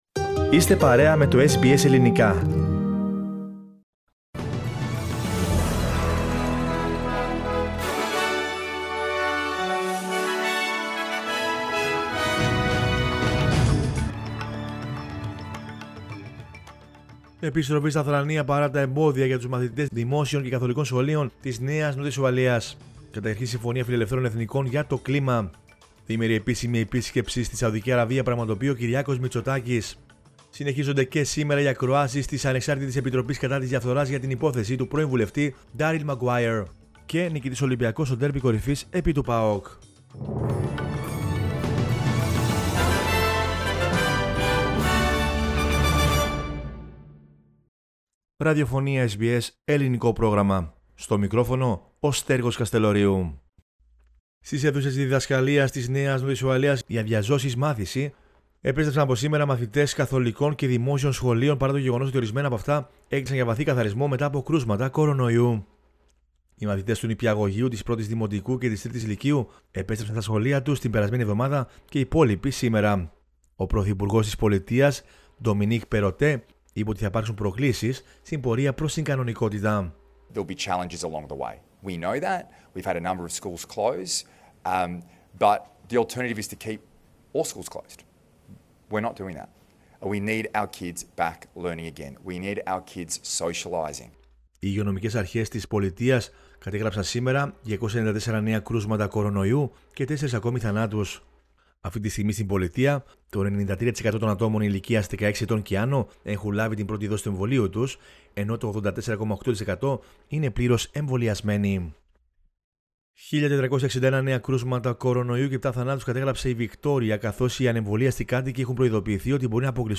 News in Greek from Australia, Greece, Cyprus and the world is the news bulletin of Monday 25 October 2021.